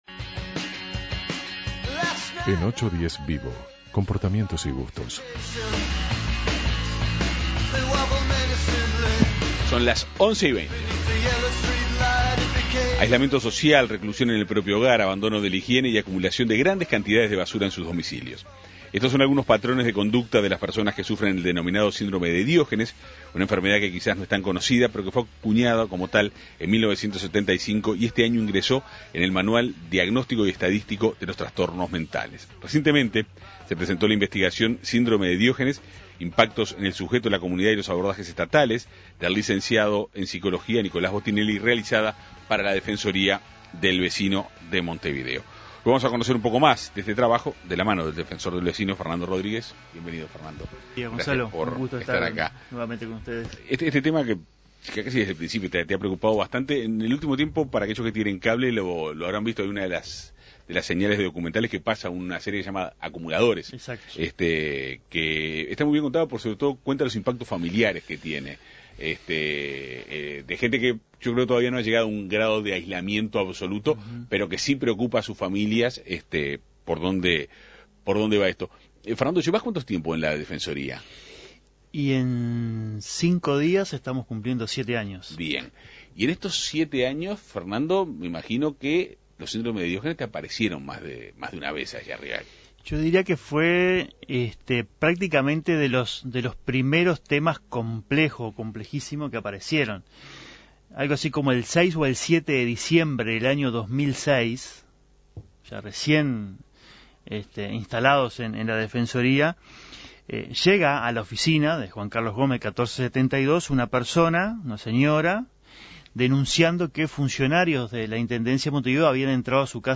Esta enfermedad se caracteriza por la acumulación de basura y la reclusión en el hogar. 810VIVO Avances, Tendencia y Actualidad conversó sobre el tema con el defensor del vecino Fernando Rodríguez.